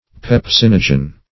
Pepsinogen \Pep*sin"o*gen\, n. [Pepsin + -gen.] (Physiol. Chem.)